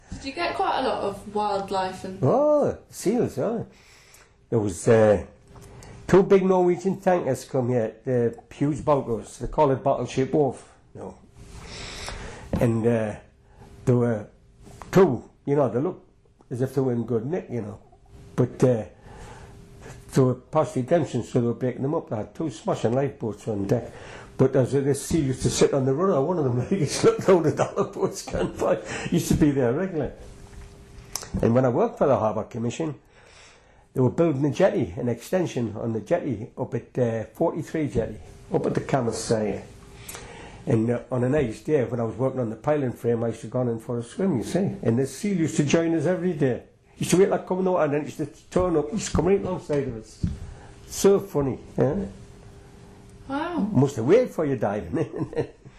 These sound files are extracts (short, edited pieces) from longer oral history interviews preserved by Northumberland Archives.